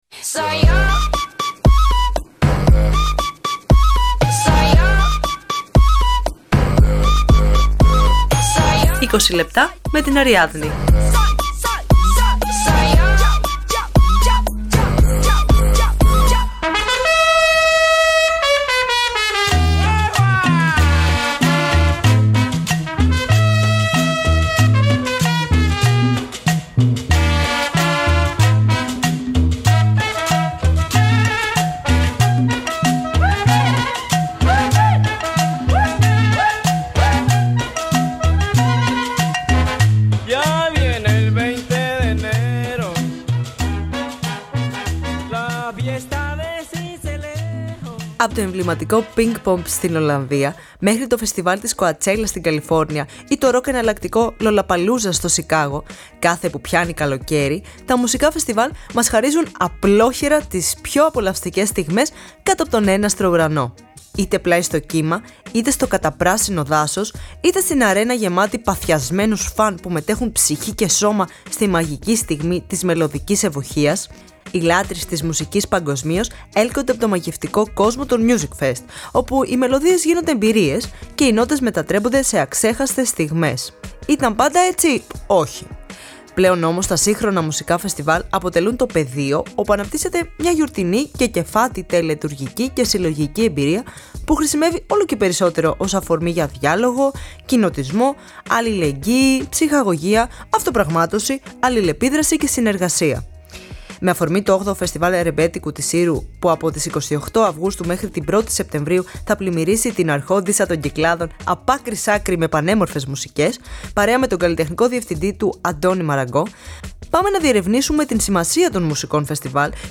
σε στυλ Καφέ Αμάν όπως του ζήτησα
που ηχογράφησαν σε live συνθήκες για τις ανάγκες αυτής της εκπομπής.